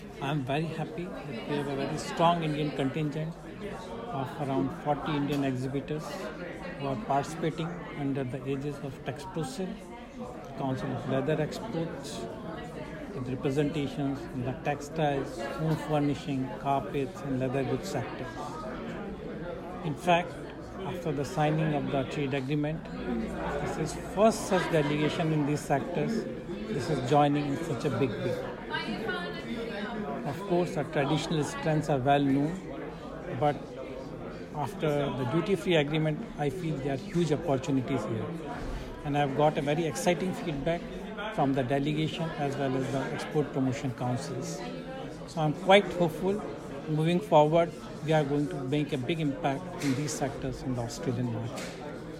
CGI, Sydney, Mr Manish Gupta speaking